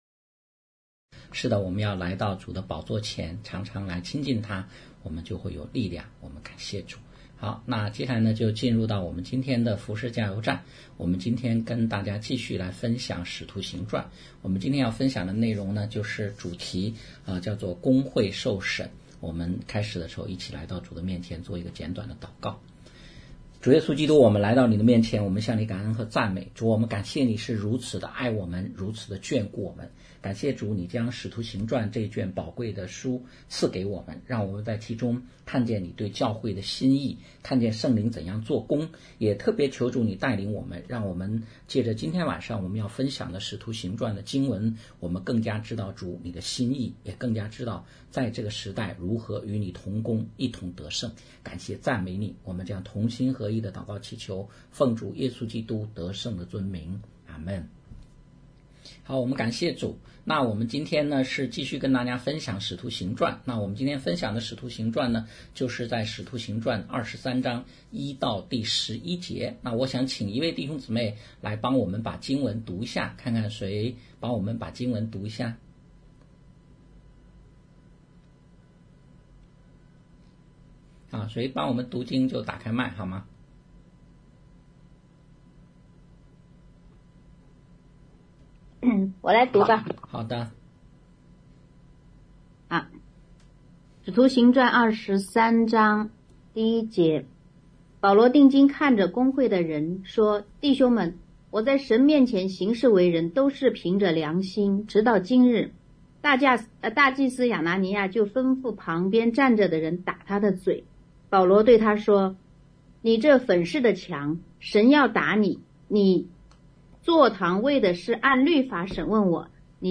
讲道录音 点击音频媒体前面的小三角“►”就可以播放 https